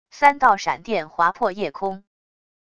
三道闪电划破夜空wav音频